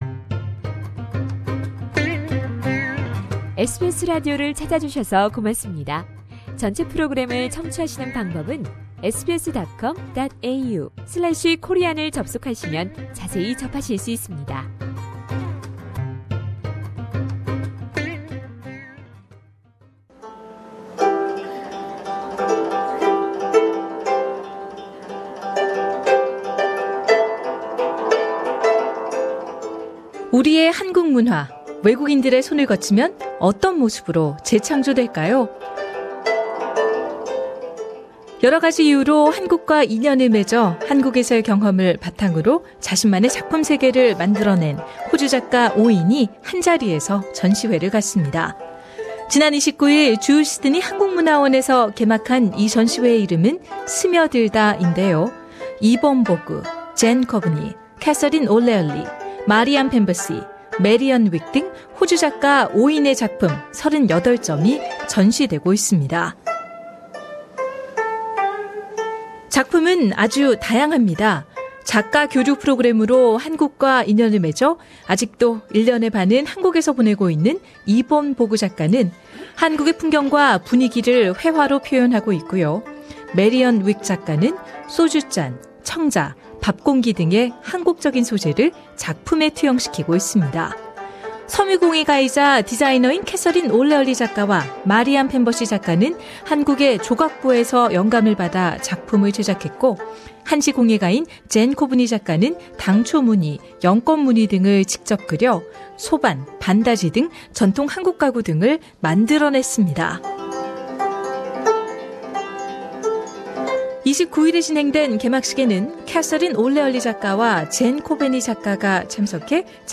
라디오 인터뷰는 상단의 팟캐스트로 청취하실 수 있습니다.